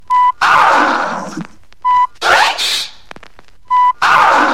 TOP >Vinyl >Grime/Dub-Step/HipHop/Juke
(scratches)